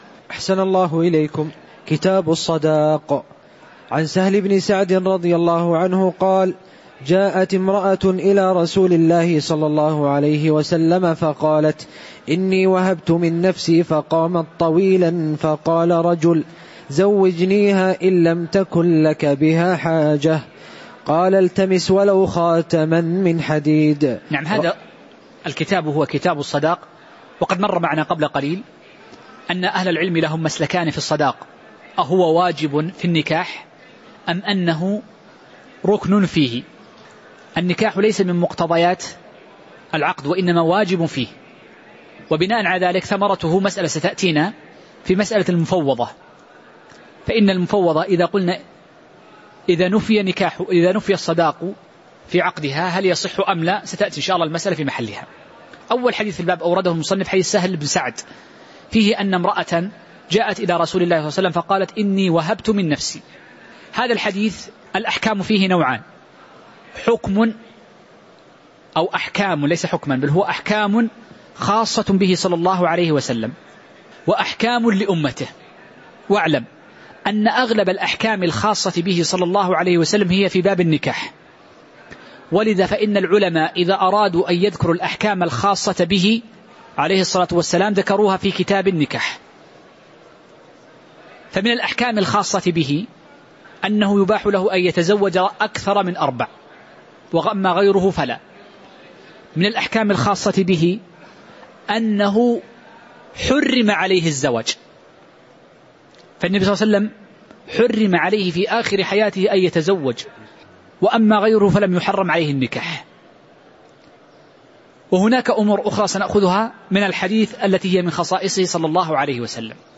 تاريخ النشر ١١ ربيع الأول ١٤٤١ هـ المكان: المسجد النبوي الشيخ: فضيلة الشيخ أ.د عبدالسلام بن محمد الشويعر فضيلة الشيخ أ.د عبدالسلام بن محمد الشويعر كتاب الصداق (01)